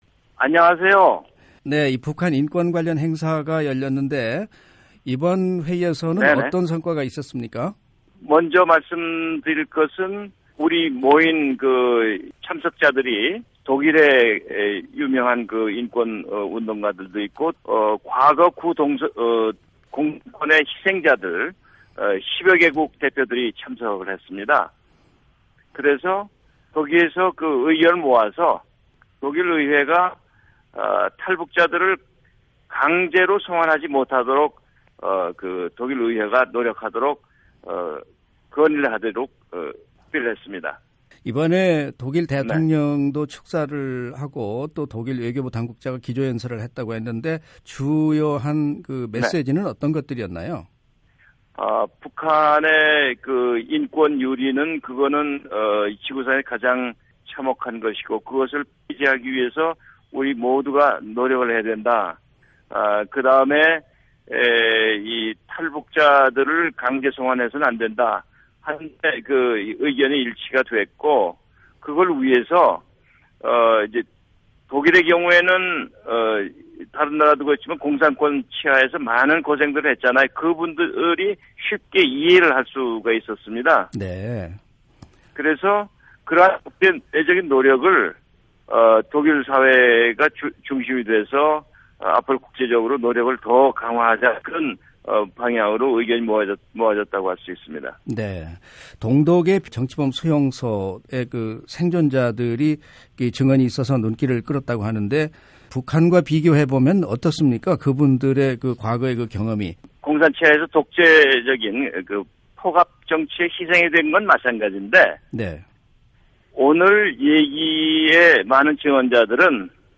독일 베를린에서 18일 제12회 북한인권난민문제 국제회의가 열렸는데요, 북한인권시민연합의 고문으로 행사에 참석한 김석우 전 한국 통일부 장관을 전화로 연결해 행사 성과에 대해 들어보겠습니다.
[인터뷰] 베를린 북한인권난민문제 회의 성과